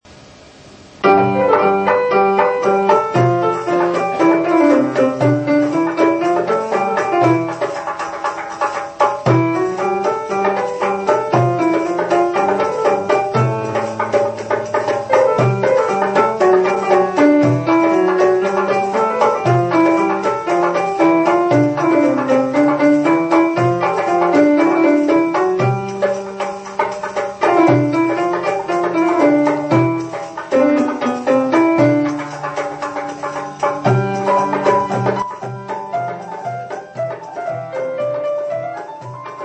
Pour écouter la version (à 4 Sol à la Rouanet) ouvrir le fichier =